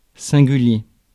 Ääntäminen
IPA: [sɛ̃.ɡy.lje]